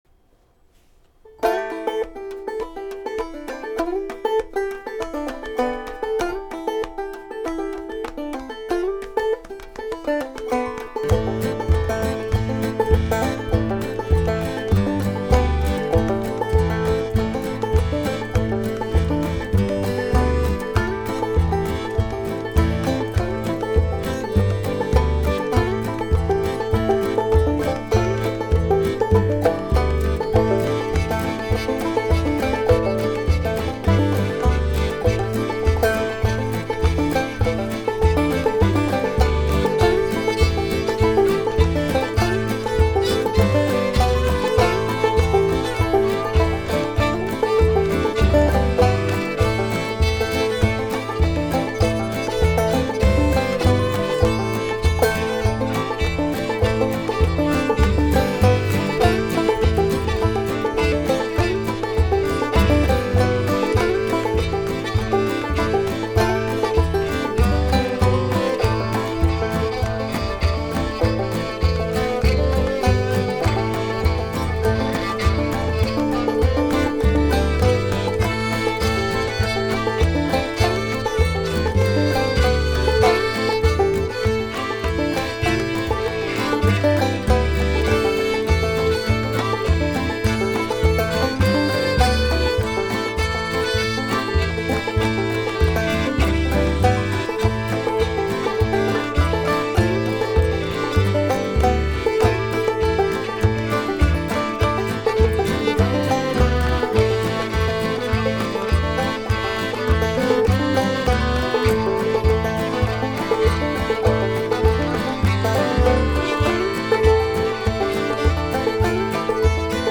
The recordings are all quite amateur, and most are, what I would refer to as, sketches, as opposed to finished and refined pieces of music.
Most are instrumental guitar, some are banjo and a few other instruments that I play with enough skill to record with them.